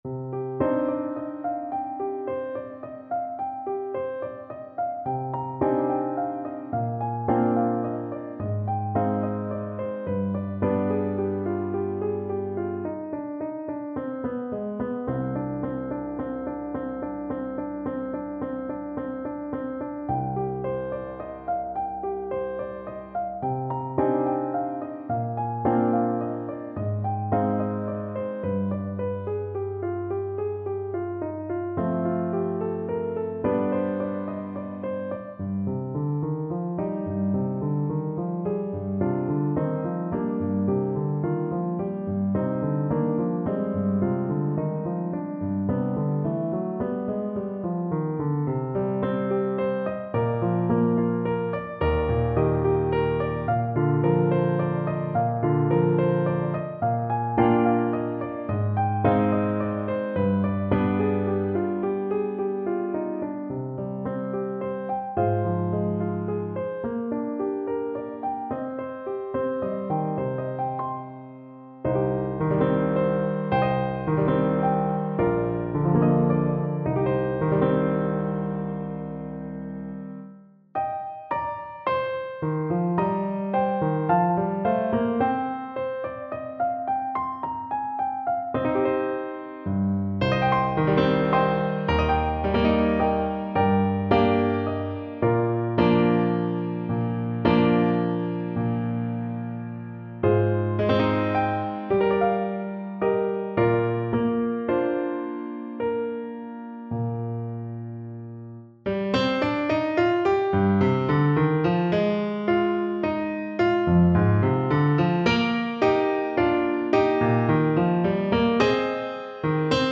A haunting arrangement of this famous British folk melody.
Fast, flowing =c.140
3/4 (View more 3/4 Music)
Traditional (View more Traditional Trumpet Music)